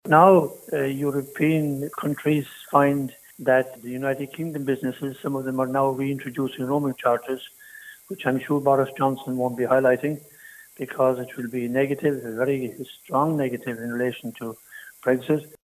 He’s now warning Irish phone users to be on their guard when calling UK numbers: